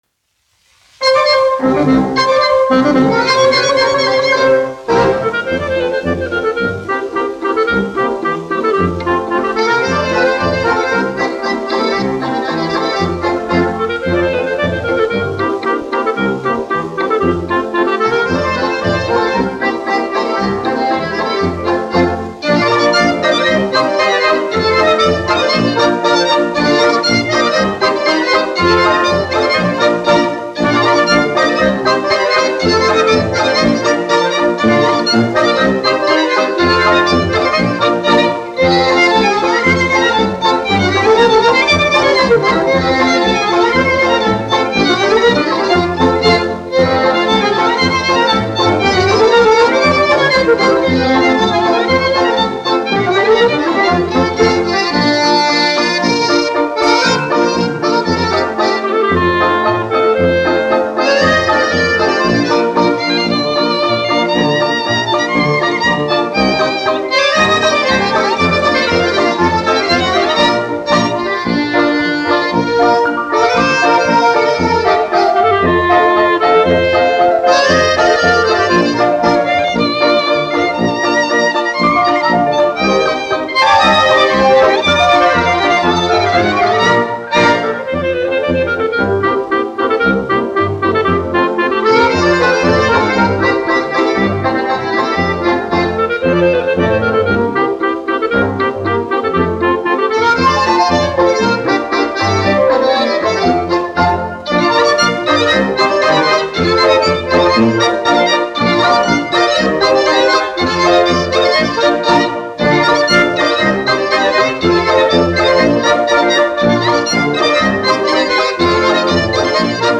1 skpl. : analogs, 78 apgr/min, mono ; 25 cm
Polkas
Deju orķestra mūzika
Skaņuplate